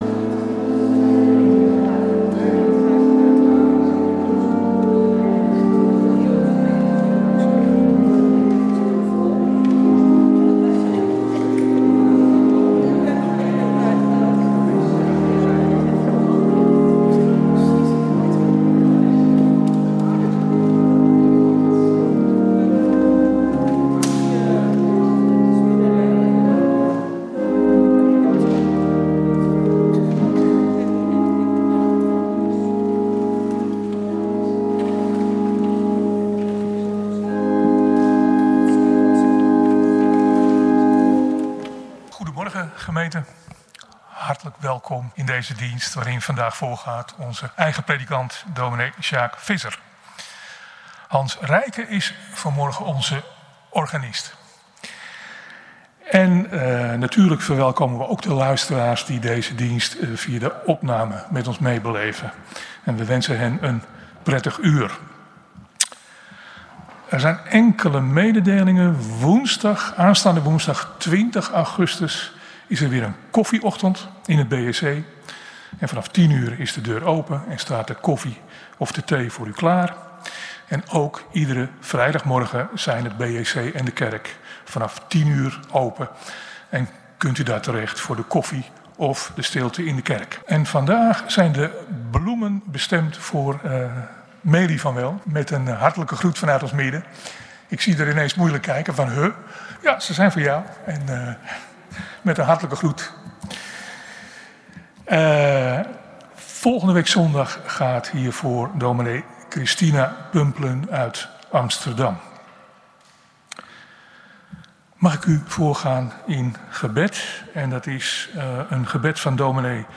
Kerkdiensten - Protestantse Gemeente Oostzaan - Zondag 10.00 uur Kerkdienst in de Grote Kerk!
Kerkdienst geluidsopname